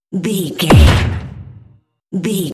Dramatic hit deep electronic
Sound Effects
Atonal
heavy
intense
dark
aggressive